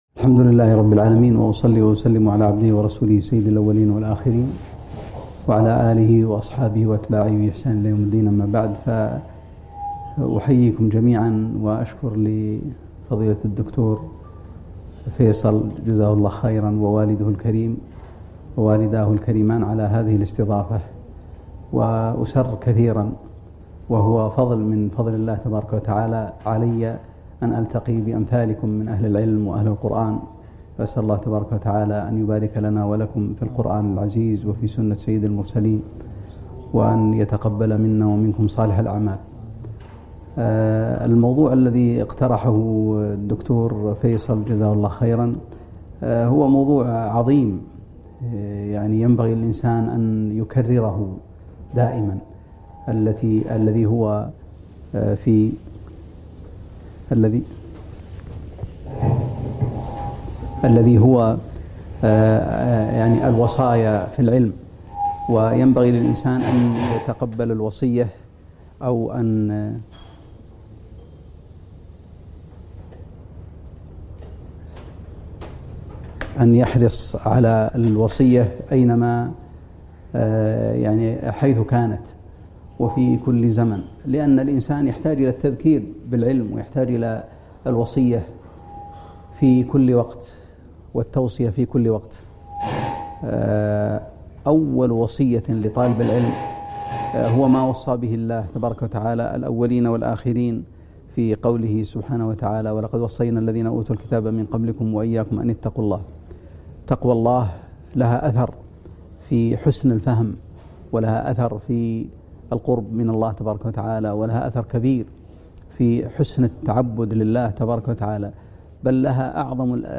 محاضرة - وصايا لطلاب العلم